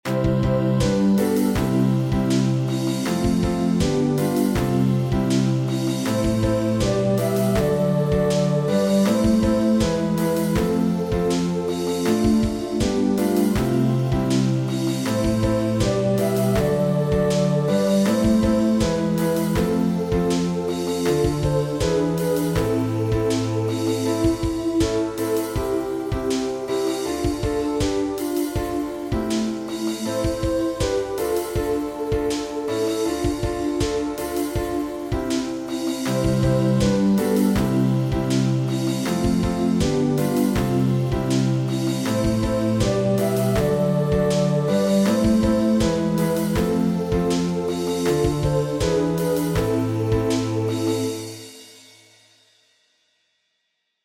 est un chant de méditation et d’espérance.
• Catégorie : Chants de Méditation